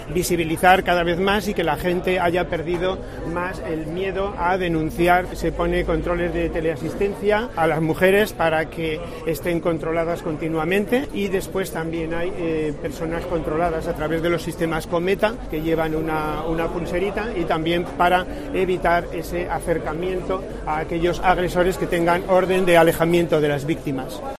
Francisco Jiménez, delegado del Gobierno en Murcia
El delegado del Gobierno en la Región de Murcia, Francisco Jiménez, ha abogado este viernes, en el acto de entrega de los reconocimientos Menina 2023, por "defender los fundamentos luchando contra los fundamentalismos" que niegan la violencia de género y por "seguir trabajando con la motivación y el recuerdo de todas las víctimas inocentes que se ha cobrado esta sinrazón".